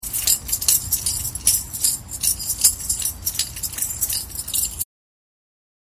これがフットタンバリンだ。
ｼｬﾝｼｬﾝｼｬﾝｼｬﾝｼｬﾝｼｬﾝｼｬﾝｼｬﾝｼｬﾝｼｬﾝ